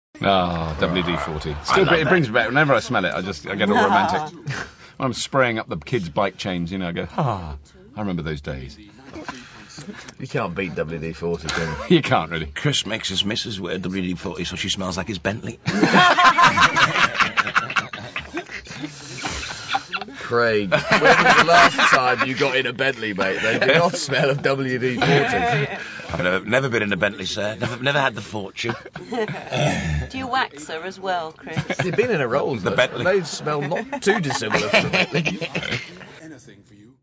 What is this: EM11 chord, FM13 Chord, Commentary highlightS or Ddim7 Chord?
Commentary highlightS